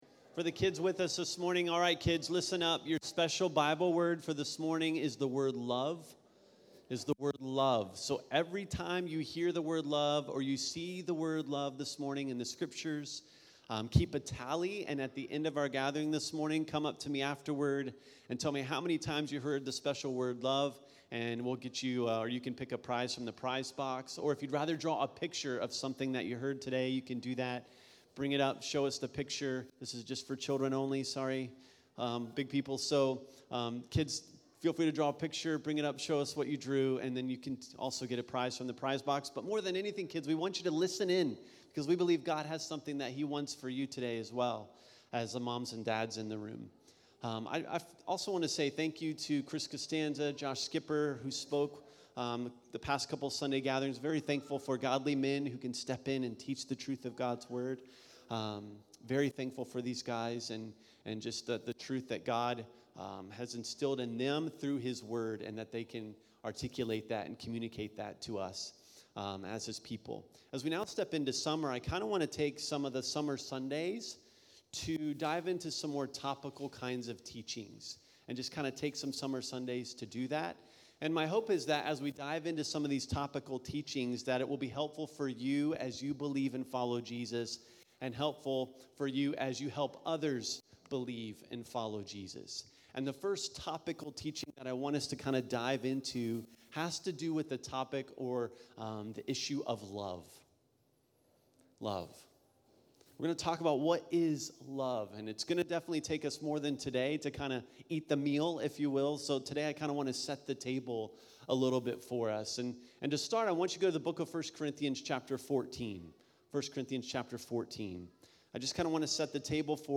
at Cincy Gathering